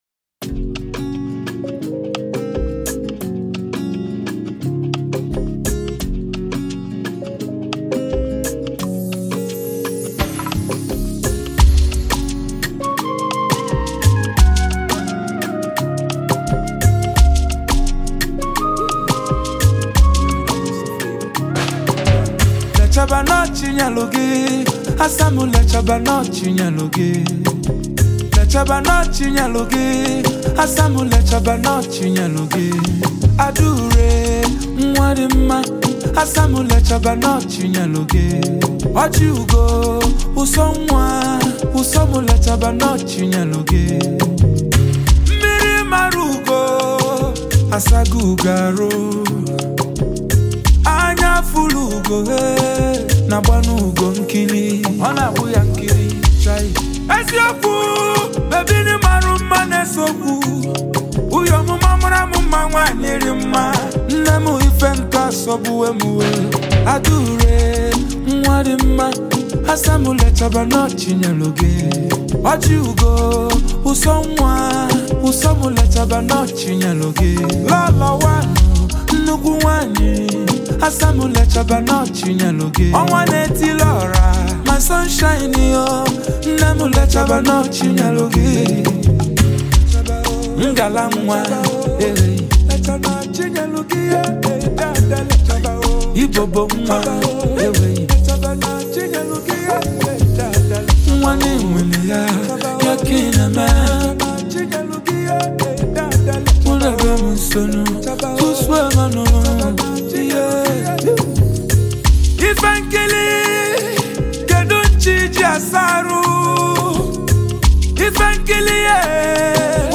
soul-stirring track